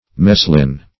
meslin - definition of meslin - synonyms, pronunciation, spelling from Free Dictionary
Meslin \Mes"lin\ (? or ?), n.